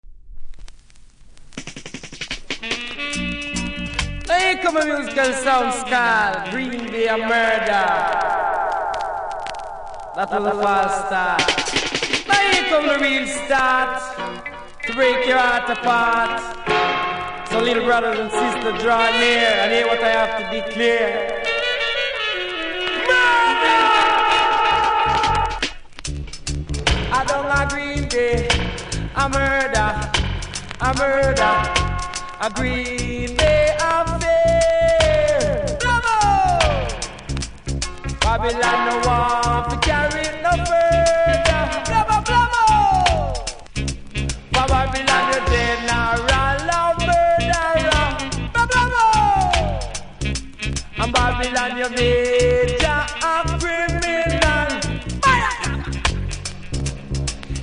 REGGAE 70'S
序盤少しノイズ感じますので試聴で確認下さい。